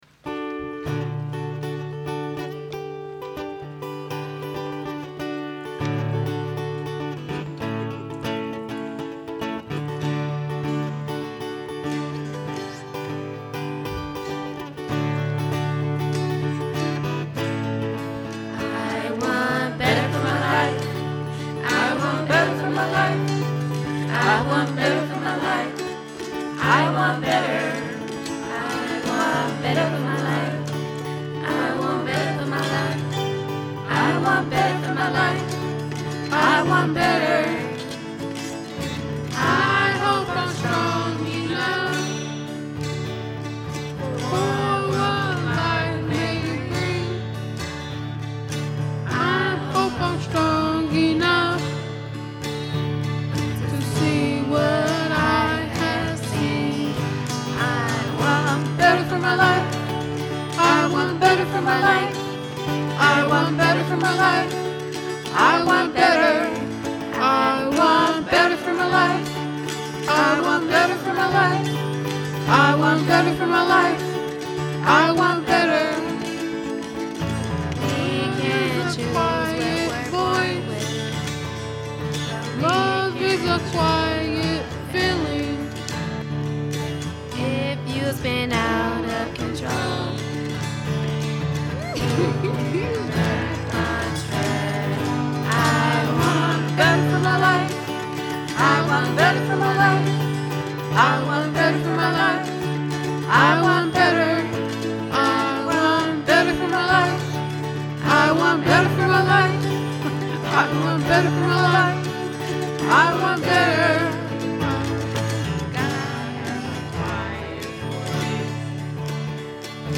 Here’s a really sweet and hopeful song written by a group not long ago.